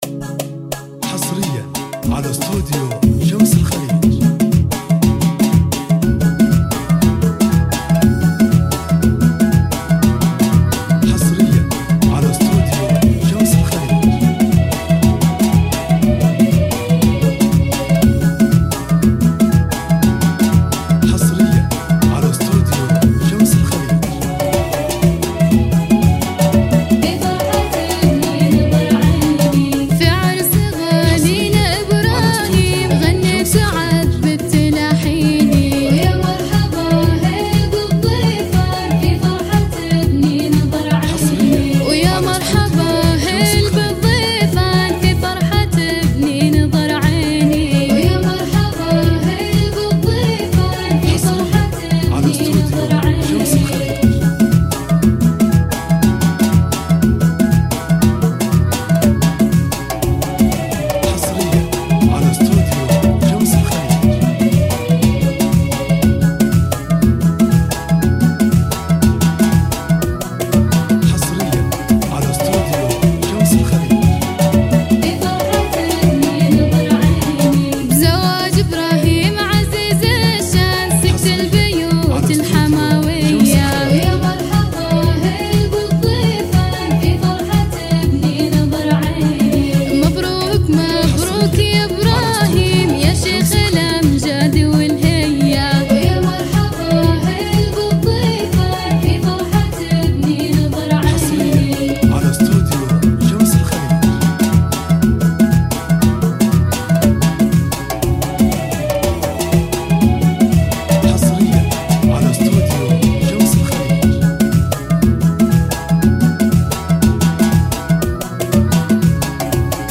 زفات كوشة
زفات موسيقى